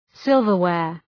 Shkrimi fonetik {‘sılvər,weər}
silverware.mp3